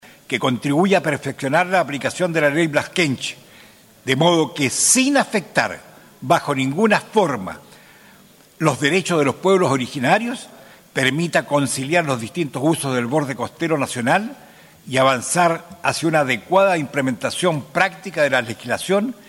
Por ello, el senador Quinteros, pidió al presidente de la Cámara Alta el oficiar con urgencia al Ministerio del Interior, la Subsecretaría de Pesca y a Conadi para que conformen una mesa de trabajo y diálogo a nivel nacional que perfeccione la aplicación de la Ley Lafkenche. El parlamentario explicó los objetivos en la materia